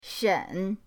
shen3.mp3